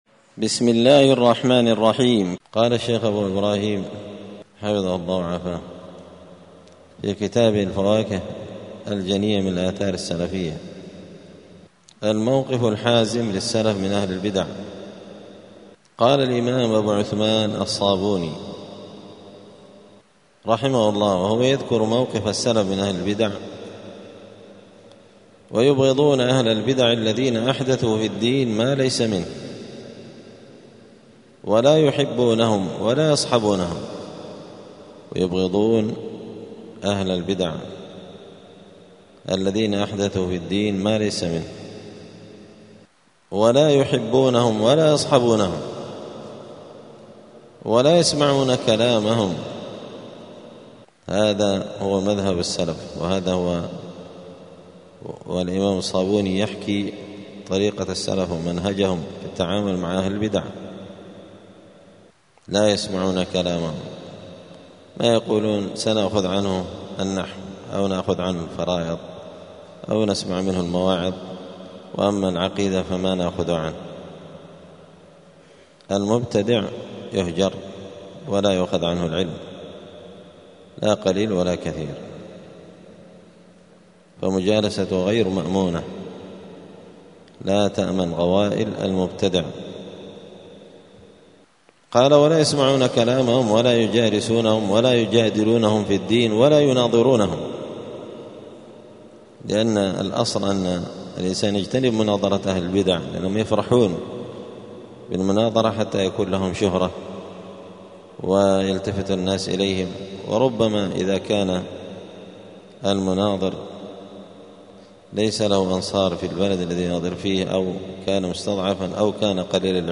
دار الحديث السلفية بمسجد الفرقان بقشن المهرة اليمن
*الدرس السادس والسبعون (76) {الموقف الحازم للسلف من أهل البدع}*